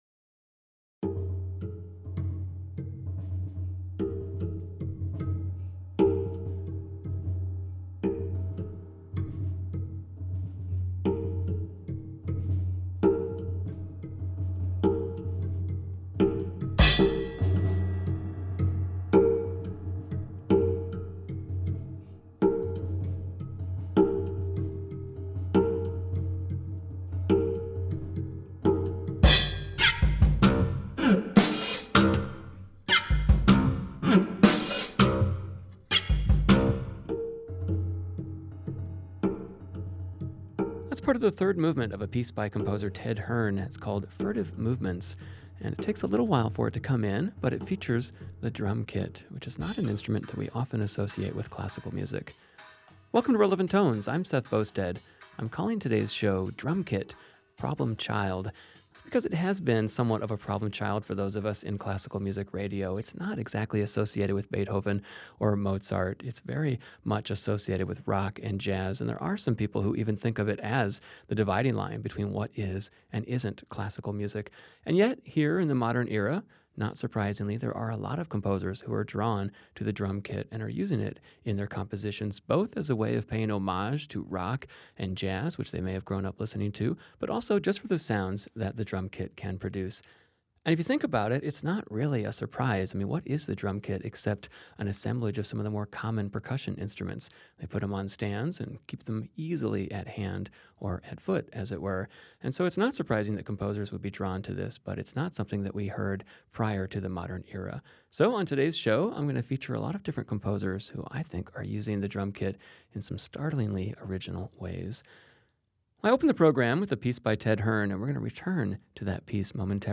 drums
cello 1:05